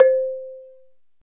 MLT MARIMB02.wav